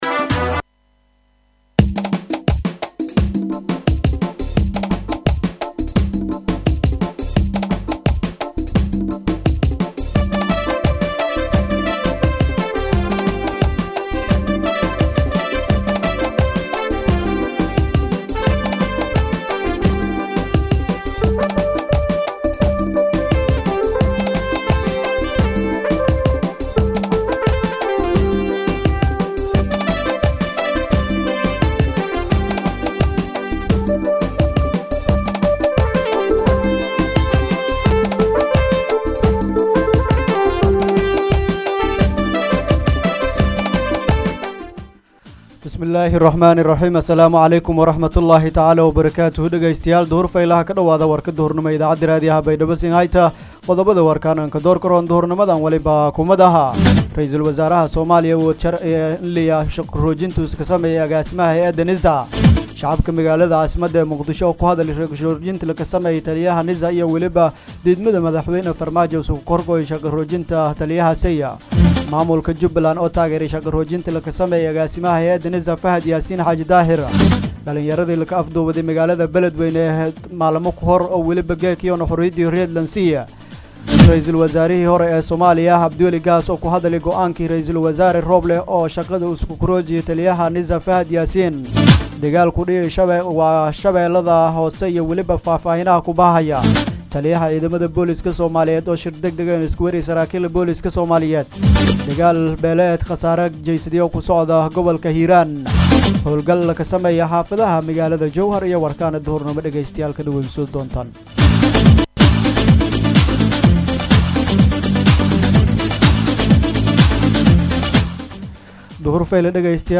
DHAGEYSO:-Warka Duhurnimo Radio Baidoa 6-9-2021